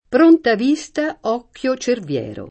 pr1nta v&Sta, 0kkLo ©ervL$ro] (Petrarca) — sim. il top. m. Cerviero (Cal.)